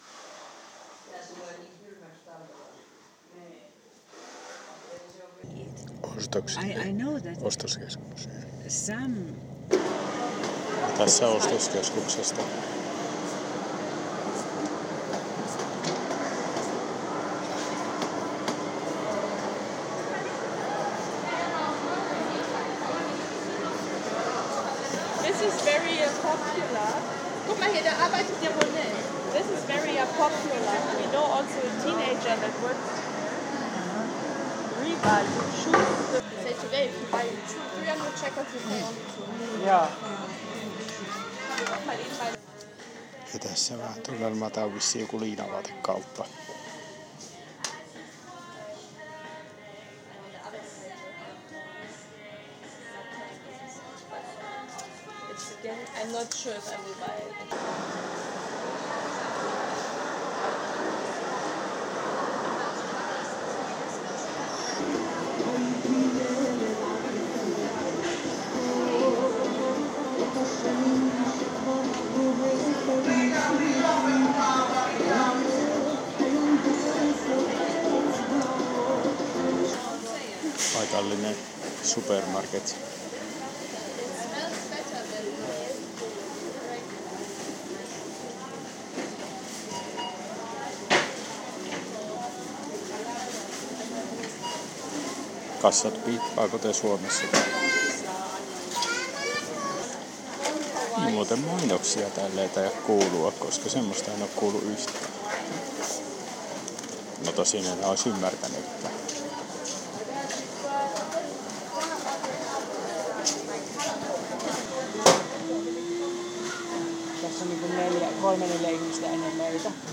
Ääniä ostoksilta